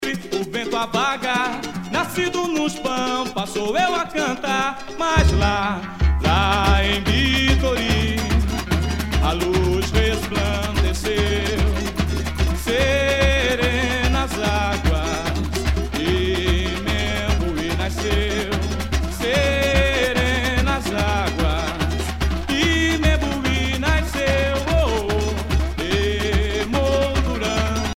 danse : samba
Pièce musicale éditée